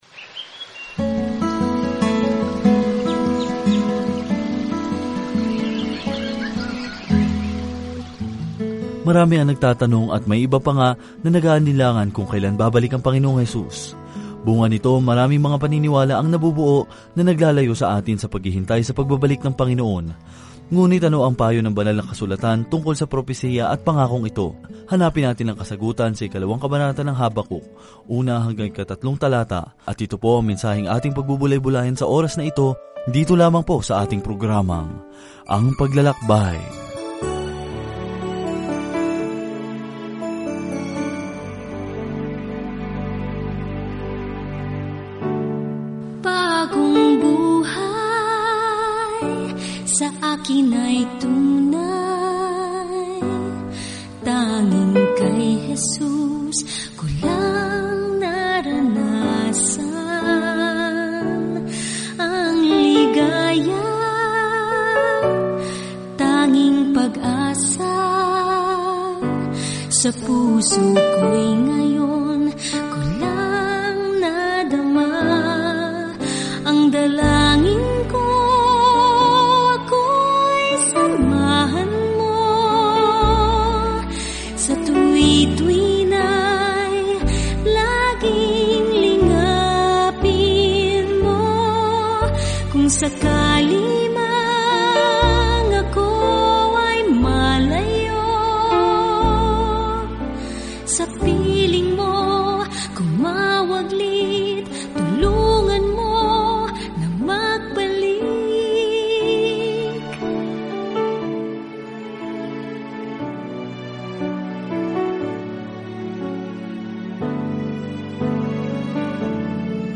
Araw-araw na paglalakbay sa Habakkuk habang nakikinig ka sa audio study at nagbabasa ng mga piling talata mula sa salita ng Diyos.